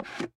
bowlgrab.ogg